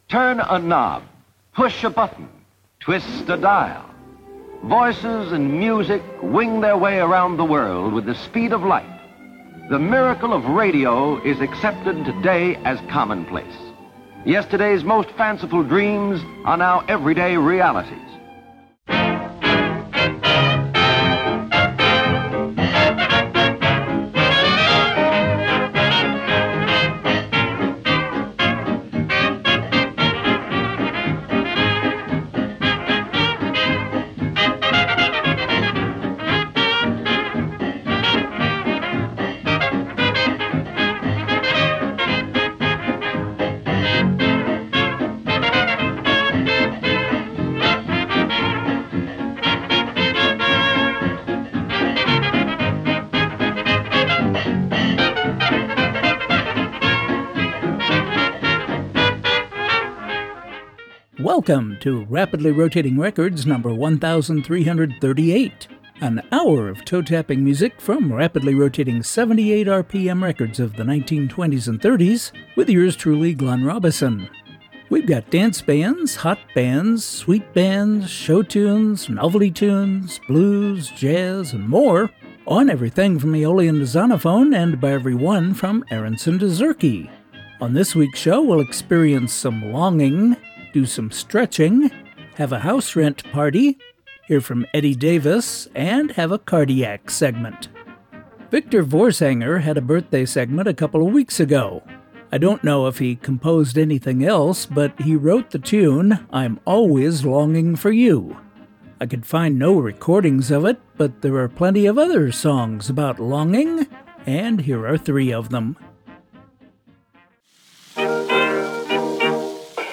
bringing you vintage music to which you can’t not tap your toes, from rapidly rotating 78 RPM records of the 1920s and ’30s.